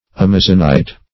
Search Result for " amazonite" : The Collaborative International Dictionary of English v.0.48: Amazonite \Am"a*zon*ite\, Amazon stone \Am"a*zon stone`\, n. [Named from the river Amazon.]